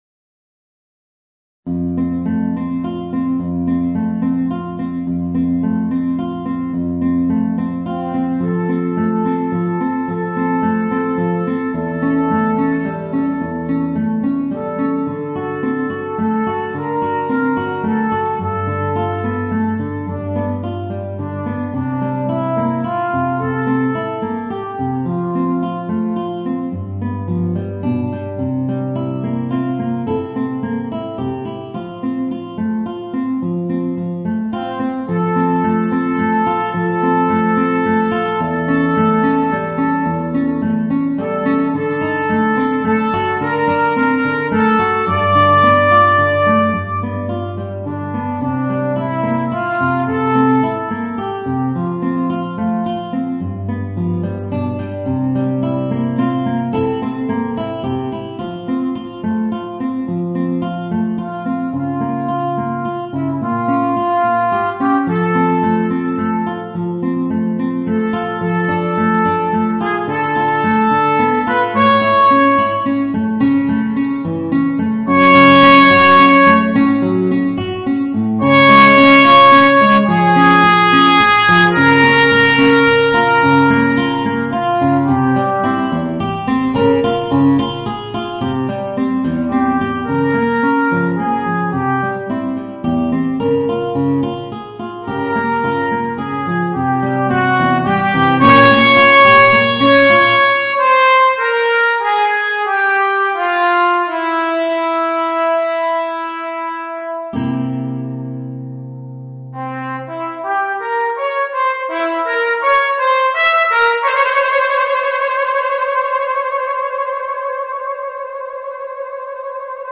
Voicing: Trumpet and Guitar